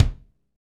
Index of /90_sSampleCDs/Northstar - Drumscapes Roland/DRM_Funk/KIK_Funk Kicks x
KIK FNK K09R.wav